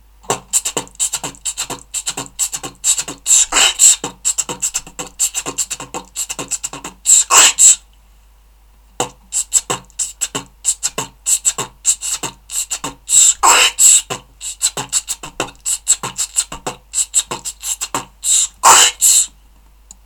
аудио с битбоксом
b tt b tt b tt b tt b tt b tt b t kch ts
b tt b tt bb tt b tt bb tt b tt bb t kch ts